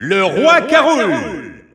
Announcer pronouncing King K. Rool in French in victory screen.
King_K._Rool_French_Alt_Announcer_SSBU.wav